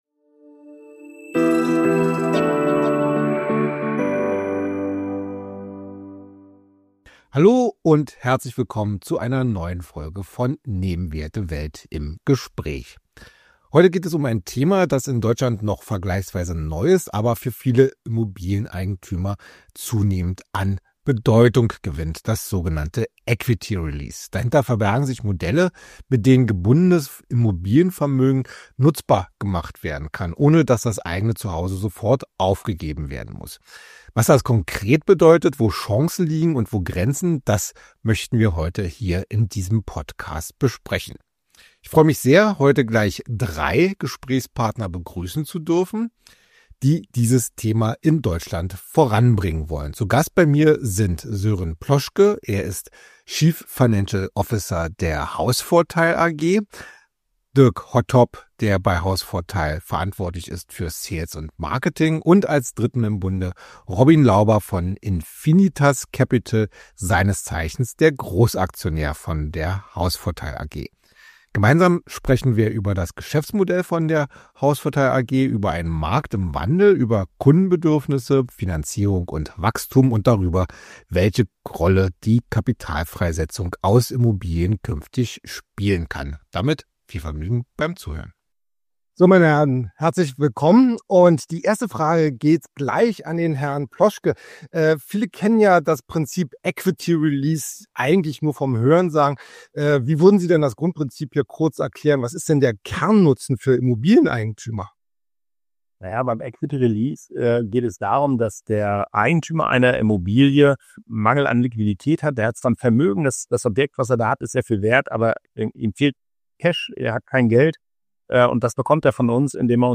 Ein Gespräch über einen jungen, aber dynamischen Markt, über Demografie, Immobilien, Finanzierung – und darüber, warum Equity Release in Deutschland künftig eine deutlich größere Rolle spielen dürfte.